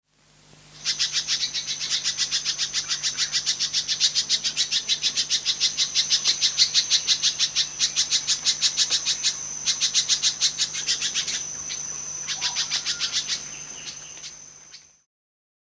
They are usually seen in ones or twos and frequently make a “tzeck” call and are most often found near flowering plants where they obtain nectar.
They have a buzzy “zick-zick” call that is made regularly when disturbed or when foraging. The song is series of rapid chipping notes and these can go on for long periods…